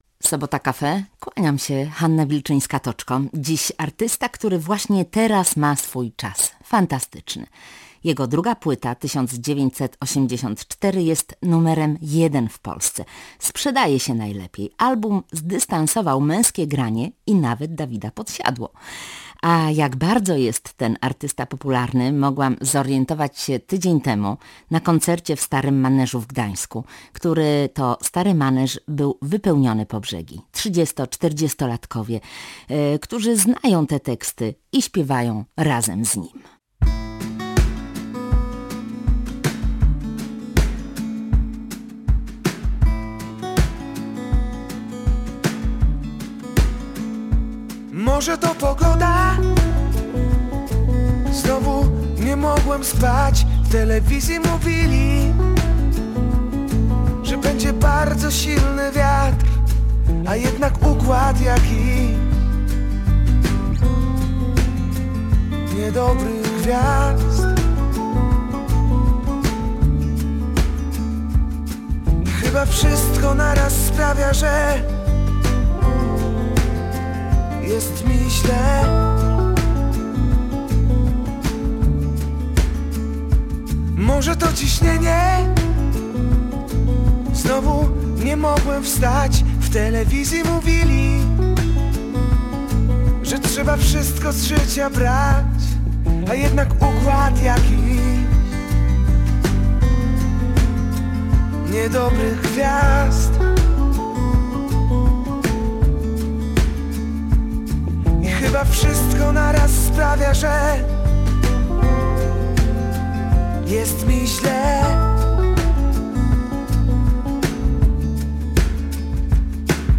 Gościem Sobota Cafe w Radiu Gdańsk był Paweł Domagała – aktor i piosenkarz.